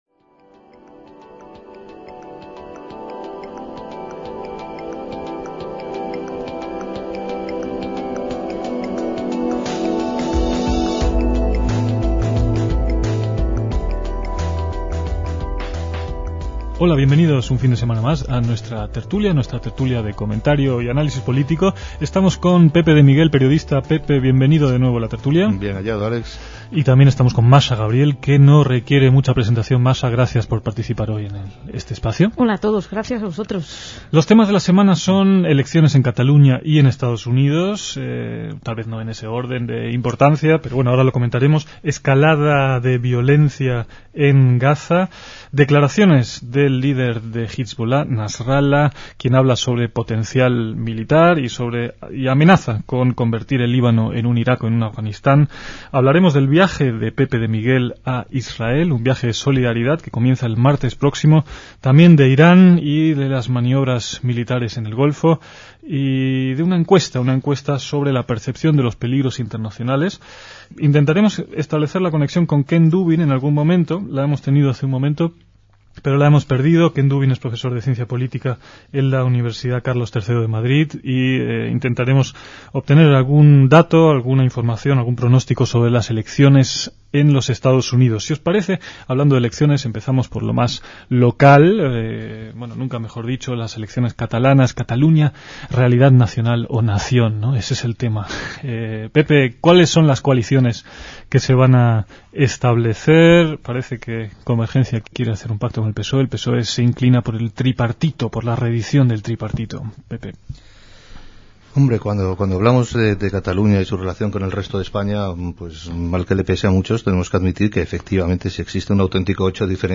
DECÍAMOS AYER (4/11/2006) - En esta tertulia se abordaron varios temas de actualidad centrados muchos de ellos en el Medio Oriente: Israel, Líbano, Irán, etc.